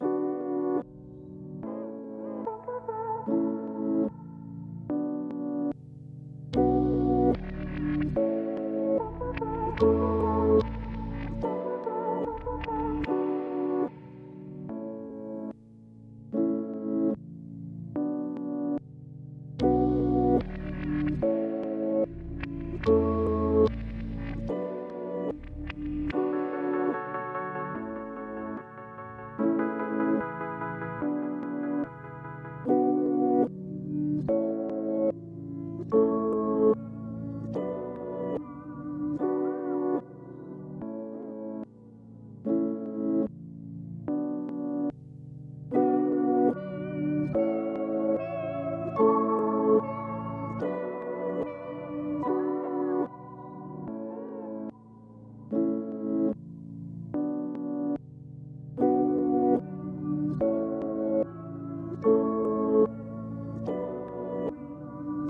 147 BPM - F# min .wav at 84ec0ab69d1e8e8d44bc0c52efa5cdf292081d6b